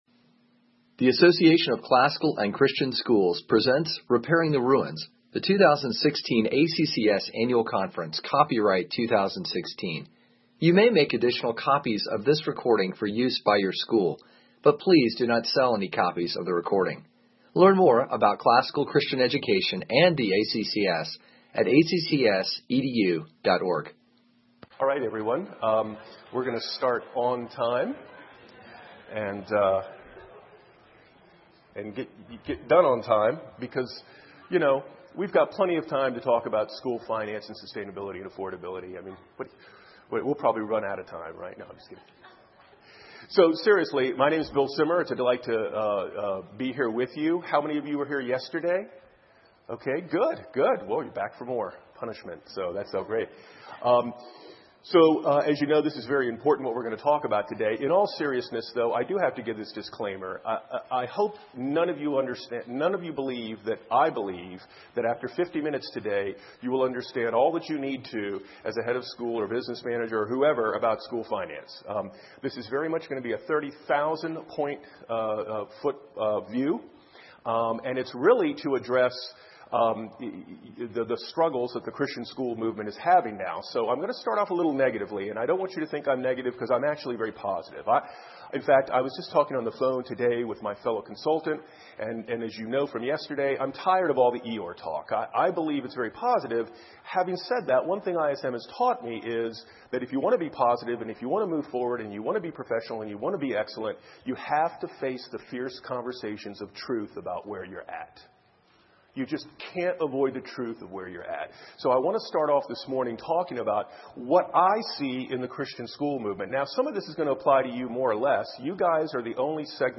2016 Workshop Talk | 1:05:36 | Budgets & Finance, Fundraising & Development, Leadership & Strategic, Marketing & Growth